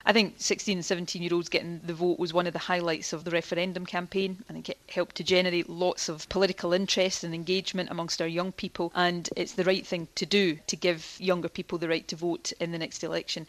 And speaking on Scotland's Talk In yesterday, she said she was committed to the cause: